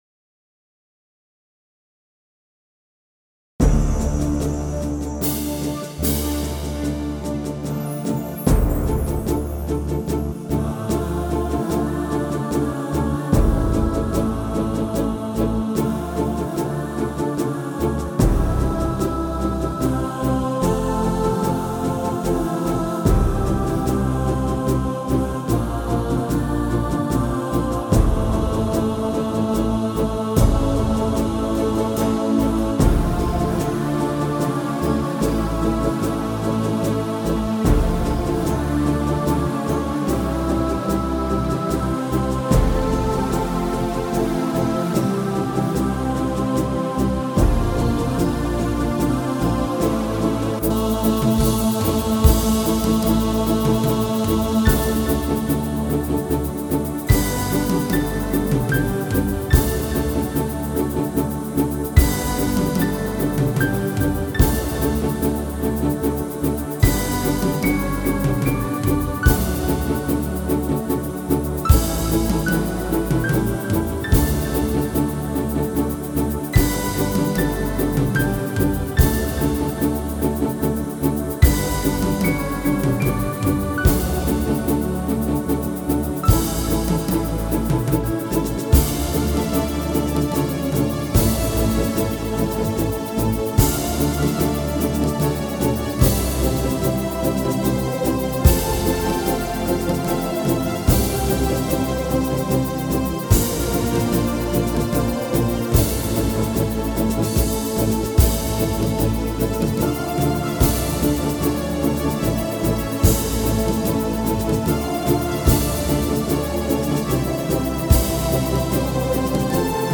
joué a la trompète et a l'orgue avec quelques paroles